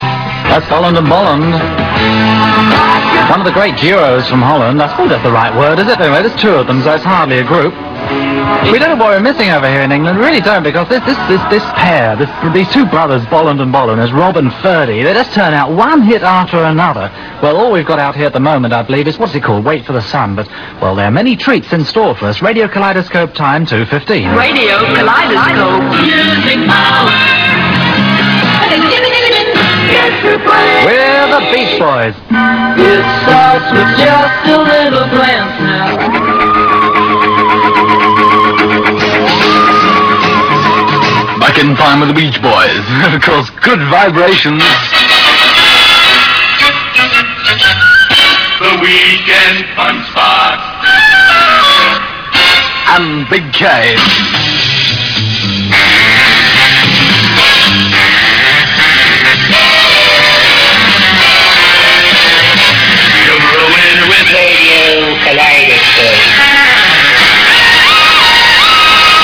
The airchecks feature the station, in its heyday, signing on at 1000 hrs on Sunday December 29th 1973 and two programming extracts from around the same period.